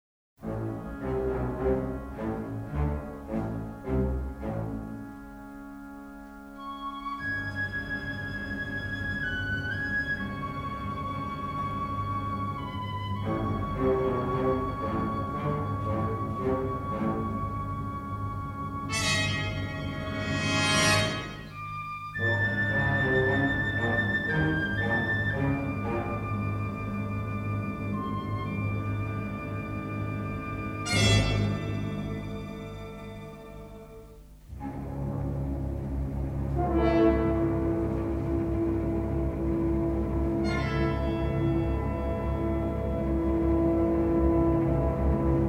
and a classic symphonic score.